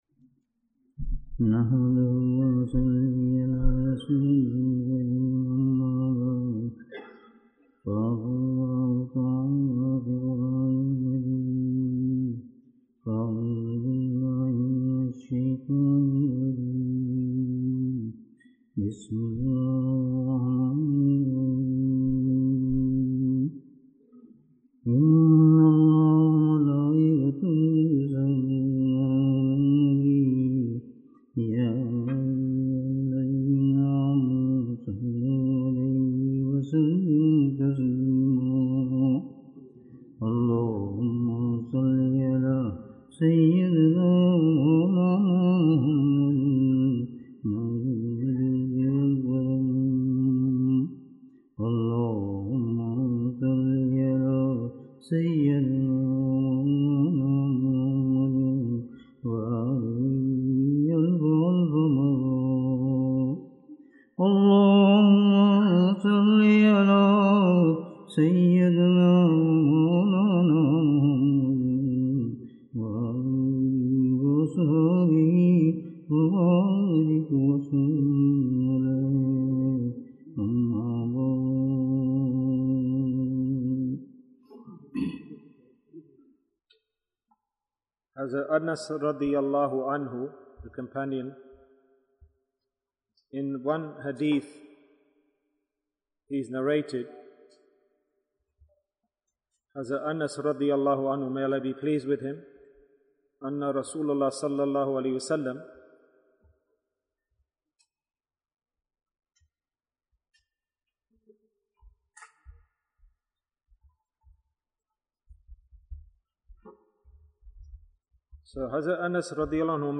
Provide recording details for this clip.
Thus, this gathering is proof that Allah loves us and we love Him.